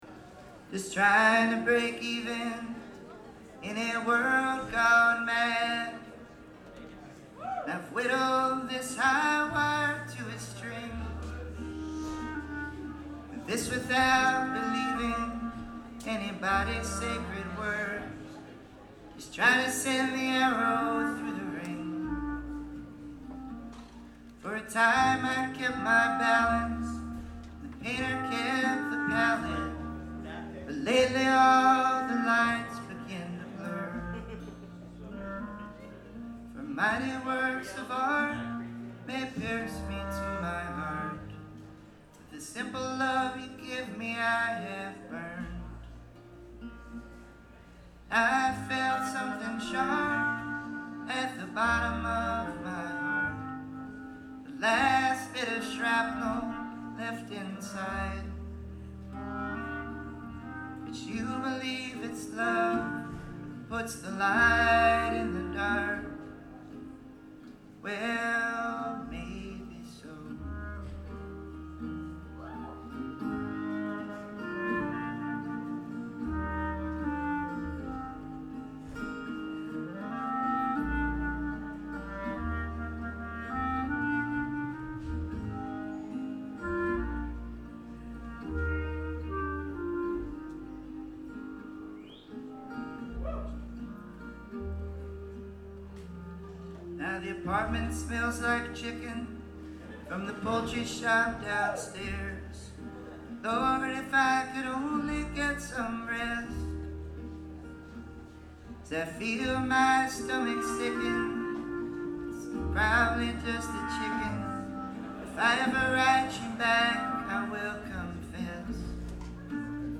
at the Bellhouse in Brooklyn